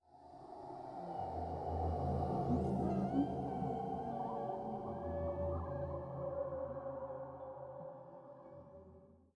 Minecraft Version Minecraft Version snapshot Latest Release | Latest Snapshot snapshot / assets / minecraft / sounds / ambient / nether / crimson_forest / addition1.ogg Compare With Compare With Latest Release | Latest Snapshot